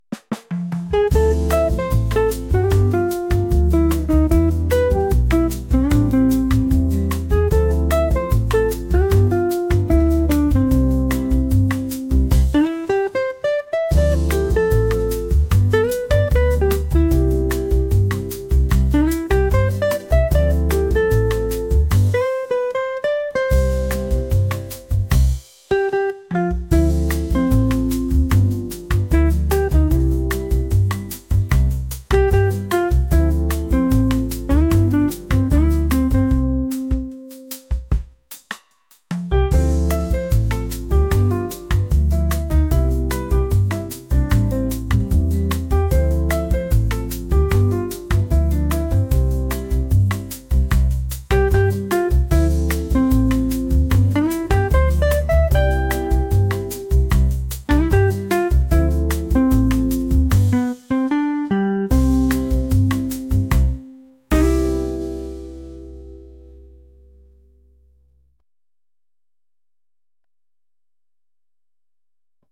mellow | latin